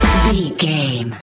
Ionian/Major
Fast
drums
electric guitar
bass guitar
Pop Country
country rock
bluegrass
happy
uplifting
powerful
driving
high energy